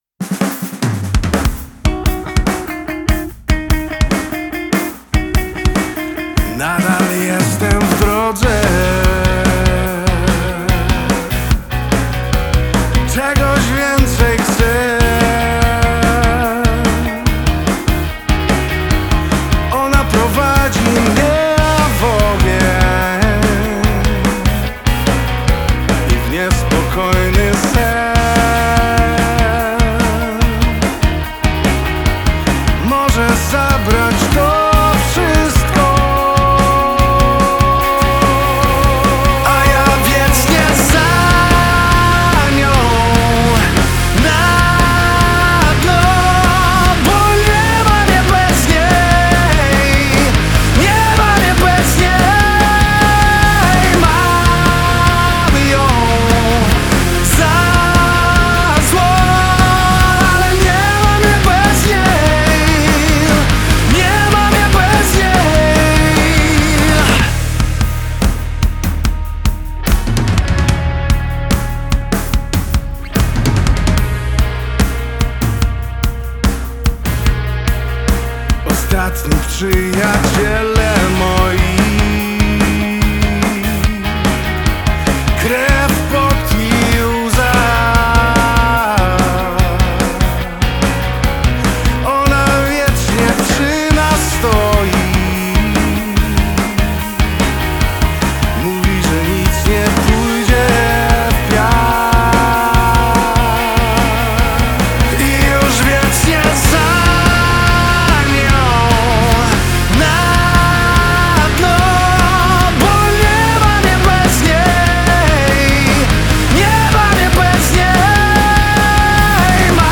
mocny rockowy duet
solidne gitarowe granie alternatywny rock